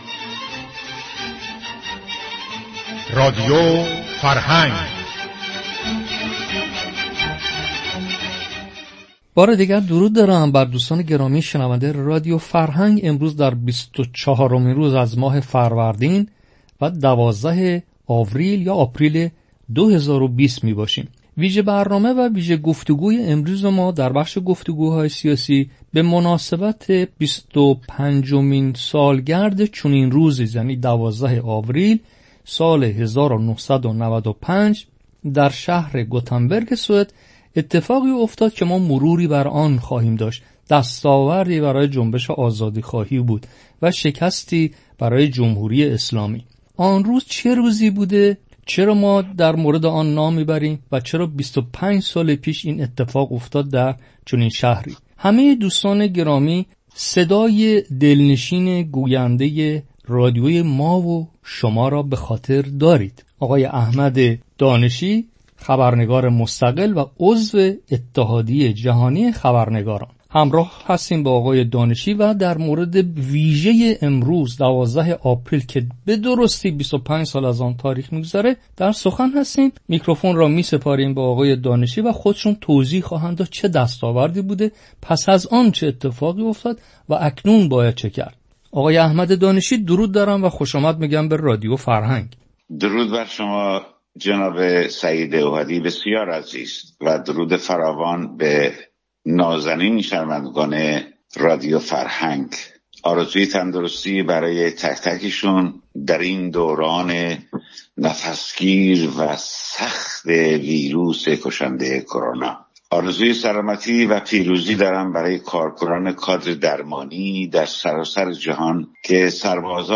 گفت و شنود رادیو فرهنگ